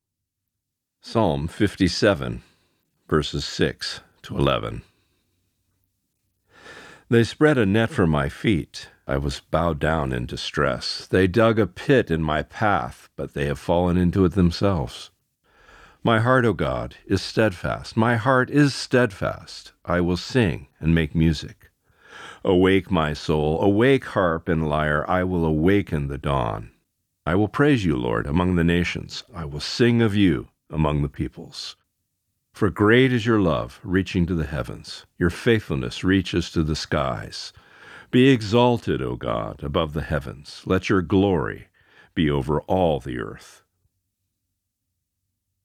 Reading: Psalm 57:6-11 (NIV)*